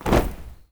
AFROFEET 5-R.wav